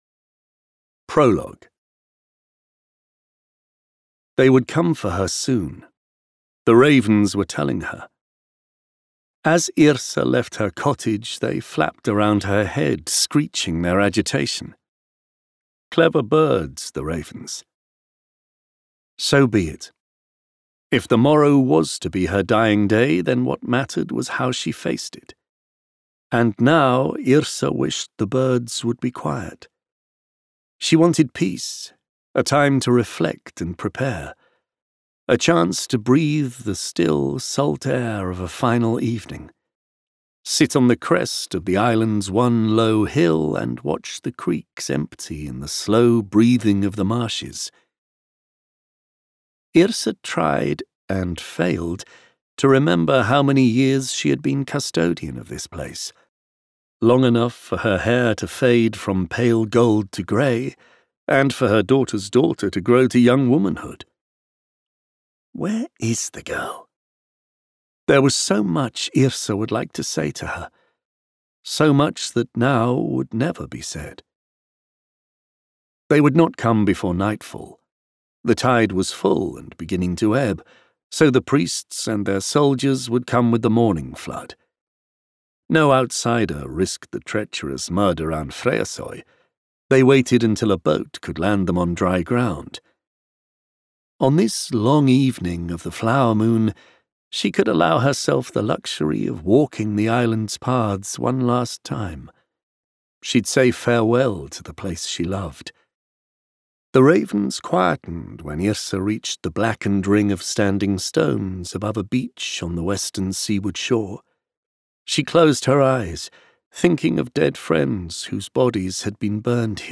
Prologue_HammerOfFate-1.mp3